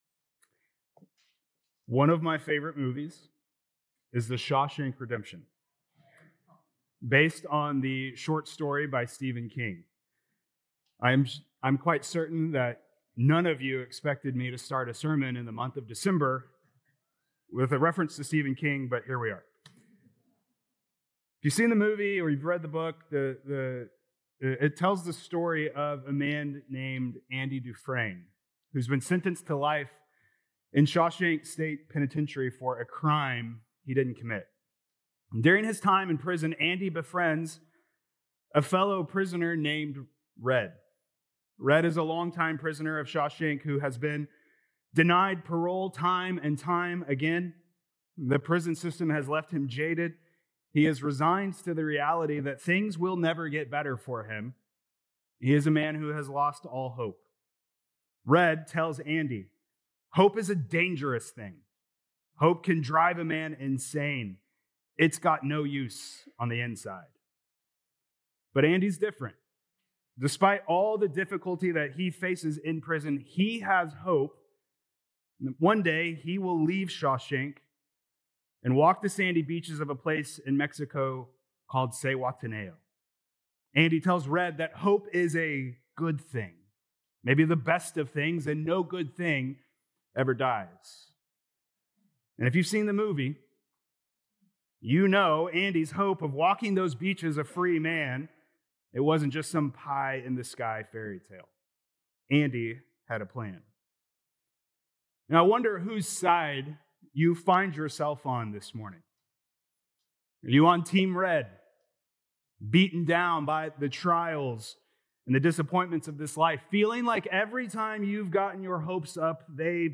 Dec 7th Sermon